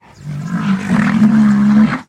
Звуки носорога
Короткое ворчание носорога